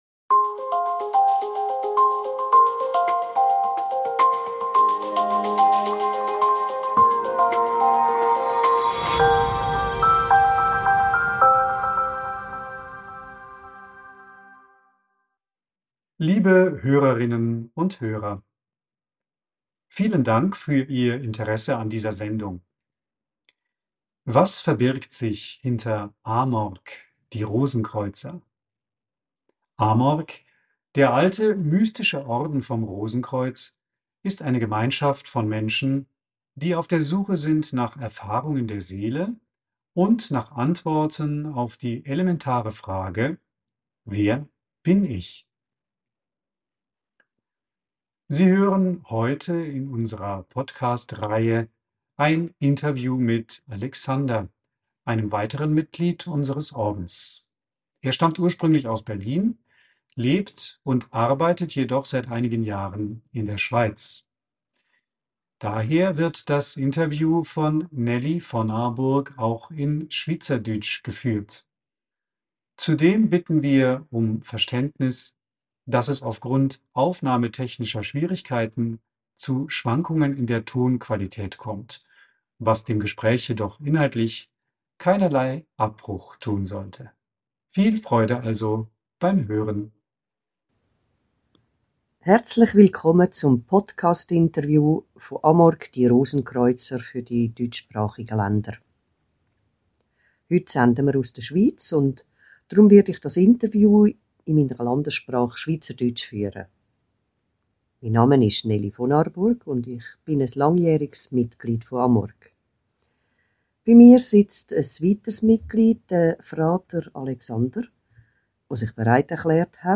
Im vorliegenden Interview auf Deutsch und Schweizerdeutsch